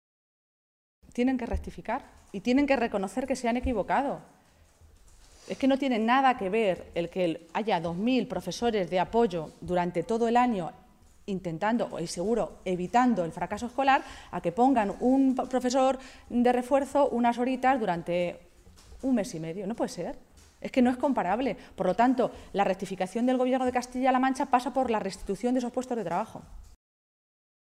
Maestre hacía este anuncio en una comparecencia ante los medios de comunicación, esta mañana, en Toledo, muy centrada en asuntos relacionados con la Educación en la que se ha referido también al inmenso y mayoritario rechazo de los claustros de profesores contra “la chapuza y la ocurrencia” del Plan de refuerzo de Cospedal para 35 días de este verano y a la privatización de las residencias universitarias, publicada hoy en el Diario Oficial de Castilla-La Mancha.
Cortes de audio de la rueda de prensa